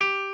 piano11_12.ogg